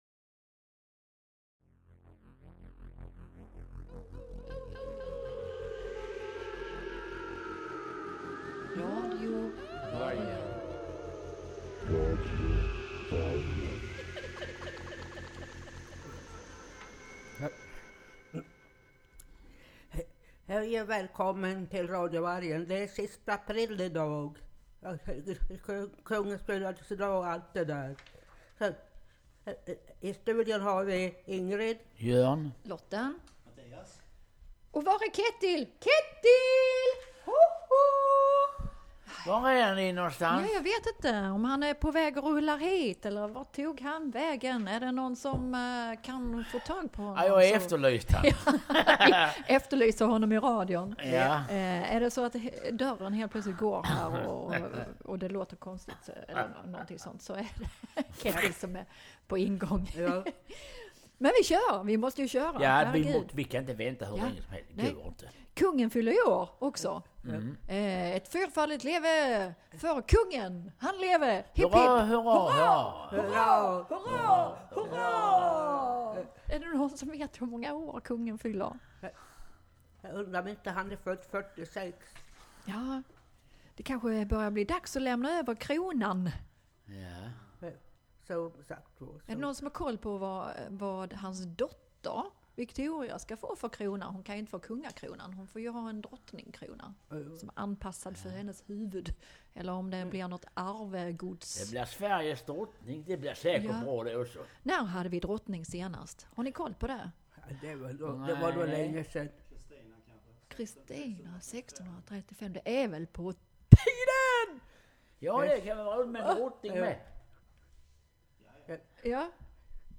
Och med fullt manskap i studion. Vi pratar om våren och spelar låtar på temat. Och vi passar även på att hylla Adam Alsing som gått bort i sviterna av Covid-19.